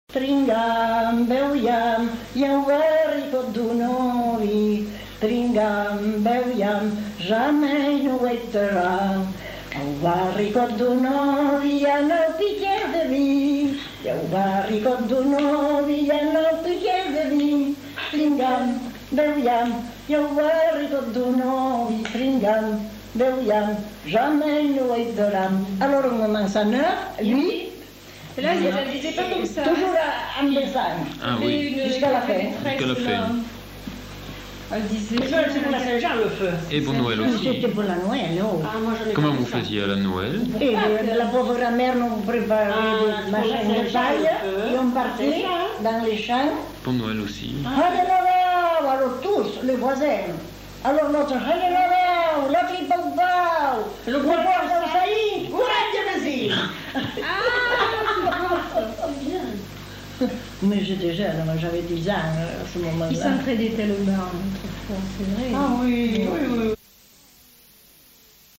Genre : chant
Effectif : 1
Type de voix : voix de femme
Production du son : chanté